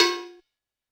Drums_K4(55).wav